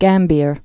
(gămbîr)